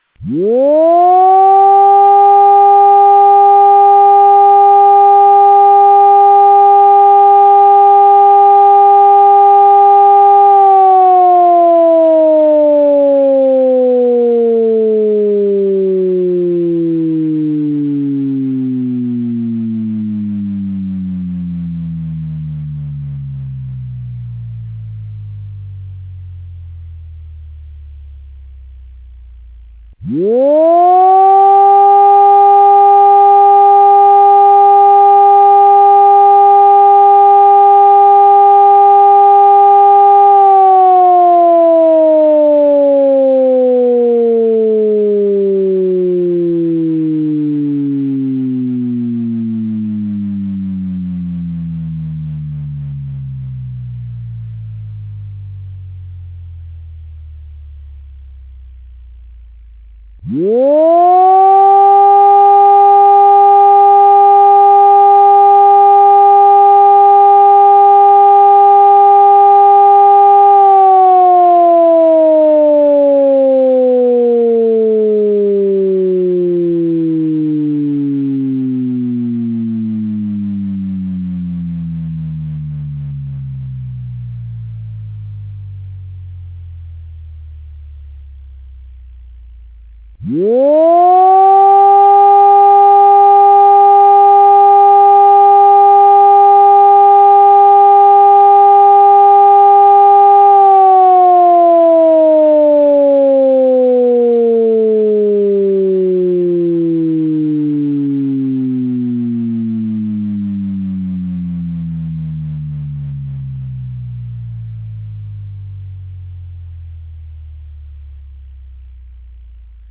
Odwołanie alarmu:
sygnał akustyczny: ciągły dźwięk syreny w okresie trzech minut, w środkach masowego przekazu: powtarzana trzykrotnie zapowiedź słowna: Uwaga! Uwaga! Uwaga! Odwołuję alarm (przyczyna, rodzaj alarmu itp.) …………… dla …………..
Plik akustyczny odwołanie alarmu
odwolanie-alarmu.wav